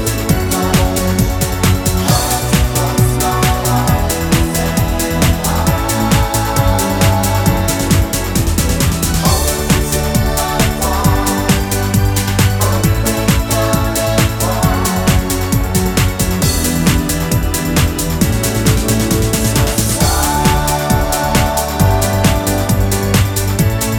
no Backing Vocals Dance 4:03 Buy £1.50